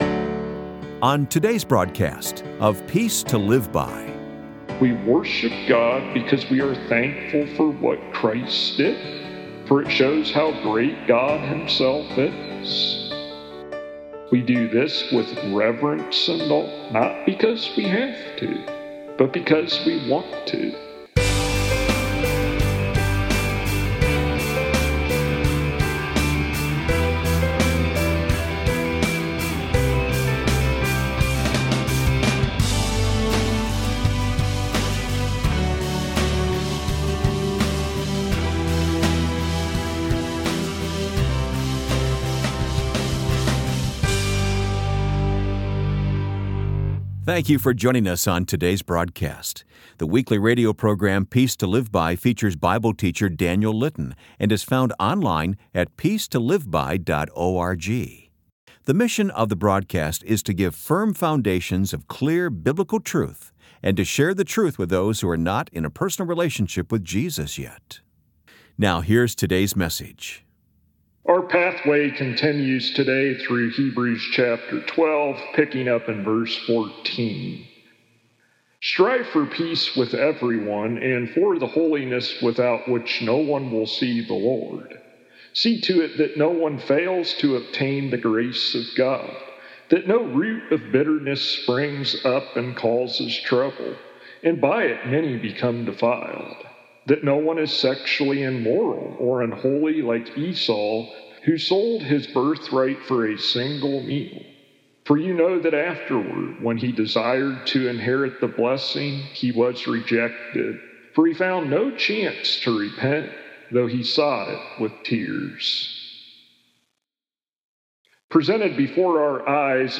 This week's broadcast contains full sermon.